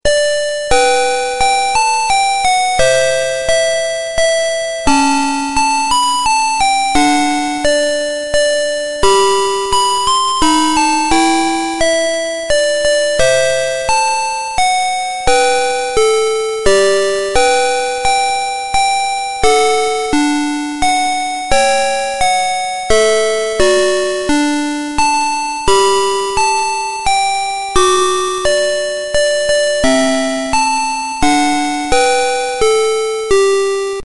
12 Christmas Songs Dual-Tong Melody
• Dual tone melody